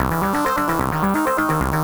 Index of /musicradar/8-bit-bonanza-samples/FM Arp Loops
CS_FMArp A_130-C.wav